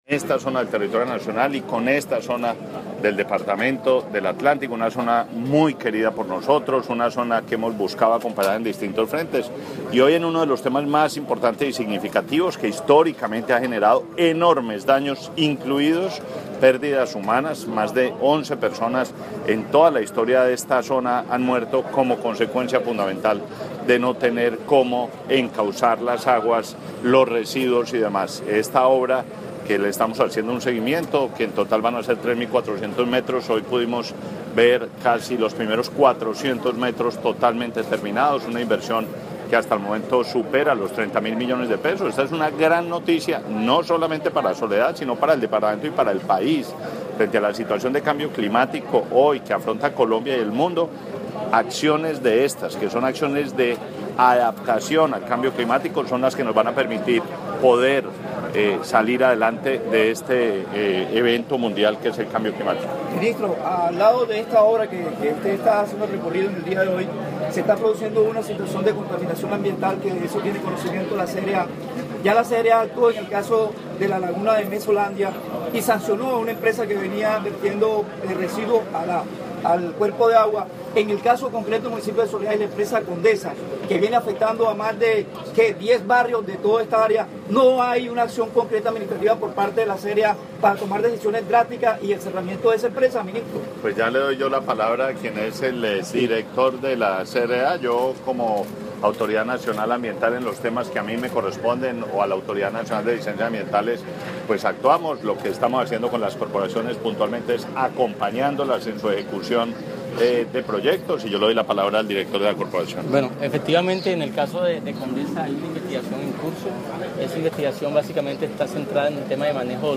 Declaraciones del Ministro de Ambiente y Desarrollo Sostenible, Gabriel Vallejo López audio
24-Declaraciones_Ministro_Vallejo.mp3